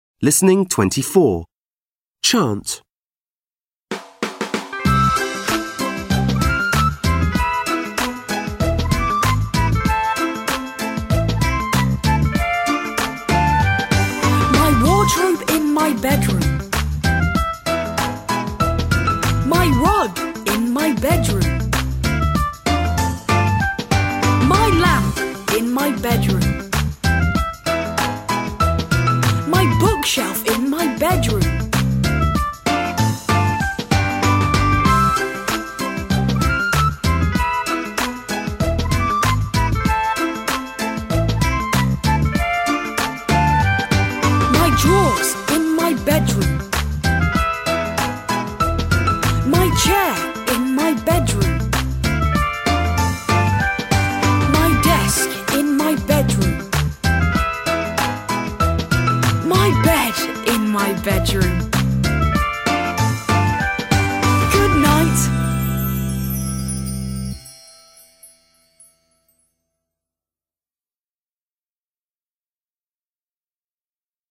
Gorąca piosenka na początek.